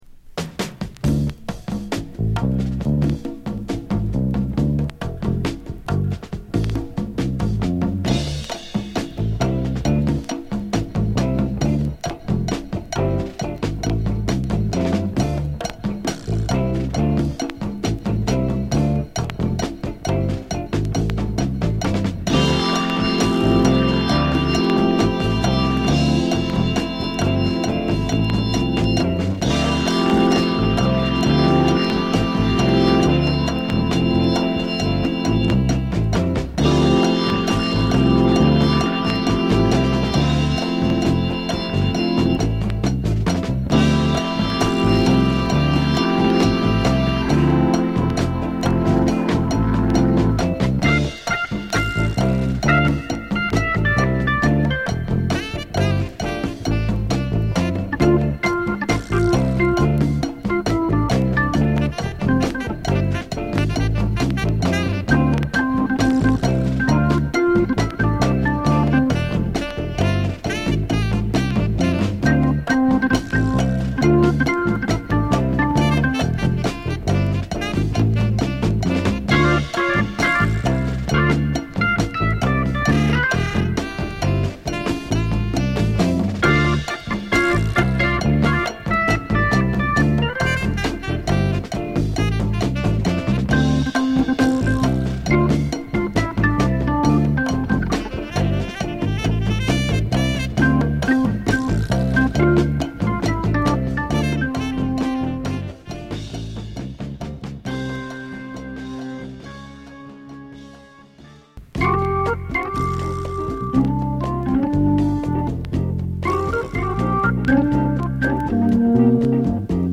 ジャズ・ファンク/ソウル・ジャズ名作！